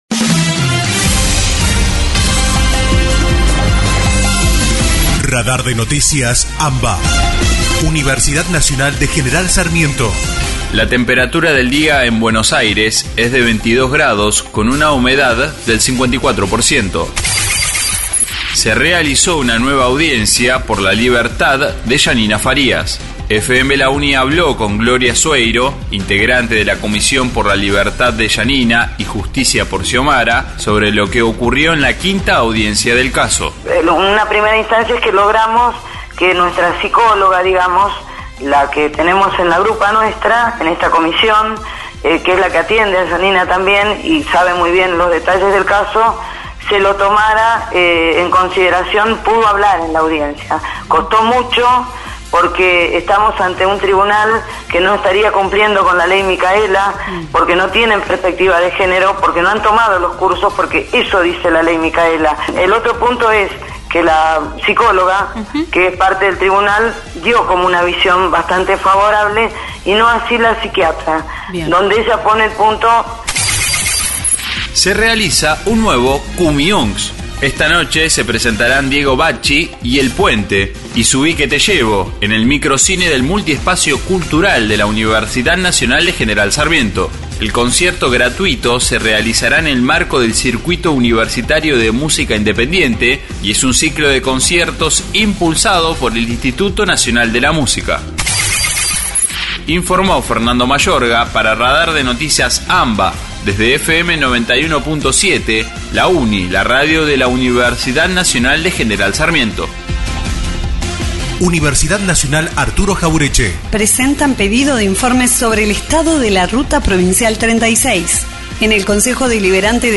Panorama informativo «Radar de Noticias AMBA» , realizado de manera colaborativa entre las emisoras de las Universidades Nacionales de La Plata, Luján, Lanús, Arturo Jauretche, Avellaneda, Quilmes, La Matanza y General Sarmiento, integrantes de ARUNA (Asociación de Radiodifusoras Universitarias Nacionales Argentinas).